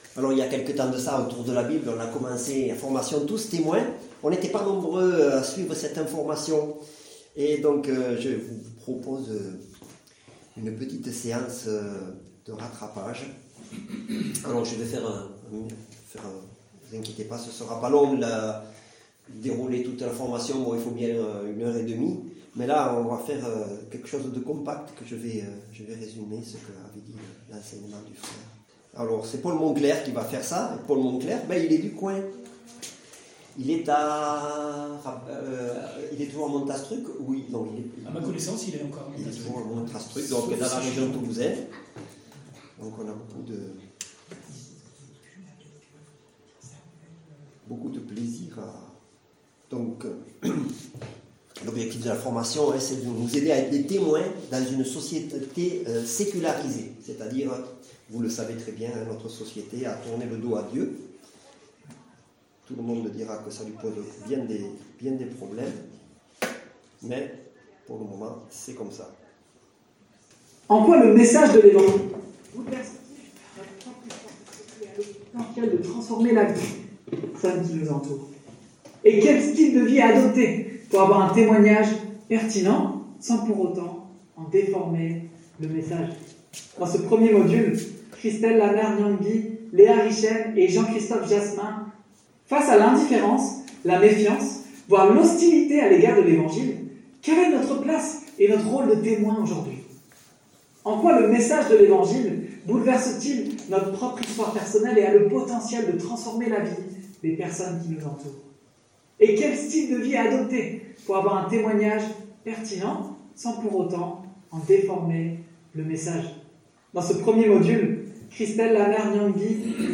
Culte du dimanche 15 décembre - EPEF